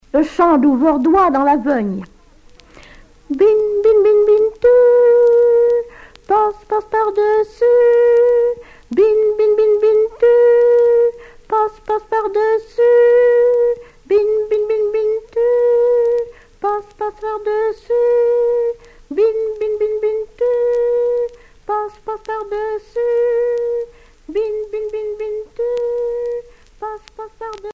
Fonction d'après l'analyste gestuel : à interpeller, appeler ;
Genre brève
Catégorie Pièce musicale éditée